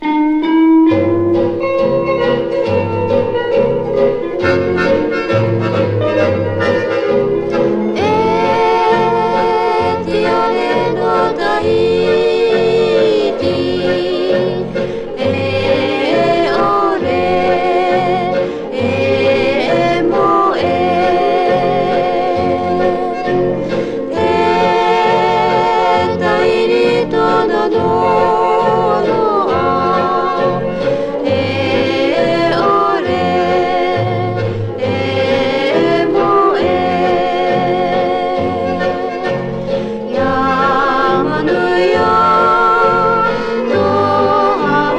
World, Field Recording, Tahiti　USA　12inchレコード　33rpm　Mono